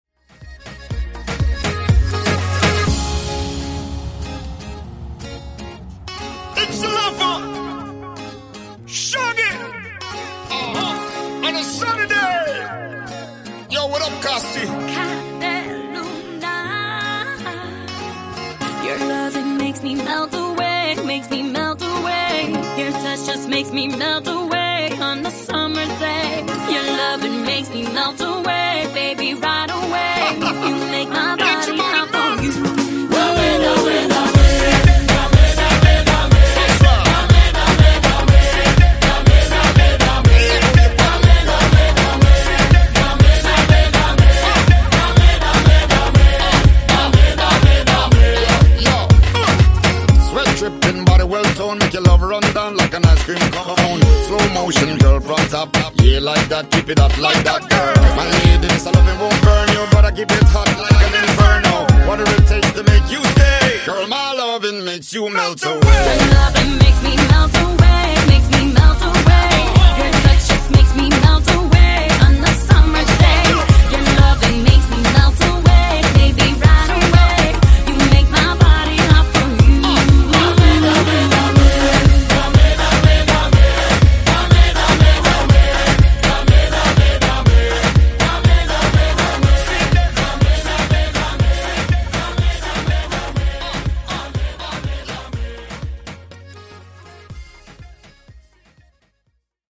Genre: RE-DRUM
Clean BPM: 126 Time